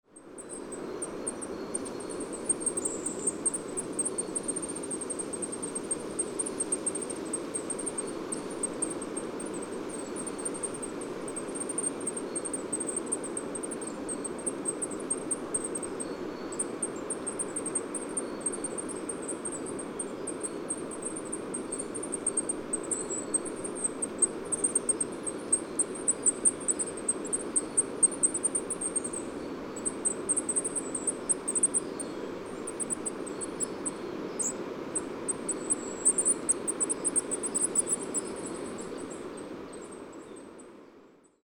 PFR10232, 1-00, 140926, Goldcrest Regulus regulus, flock, series of calls,
Darßer Ort, Germany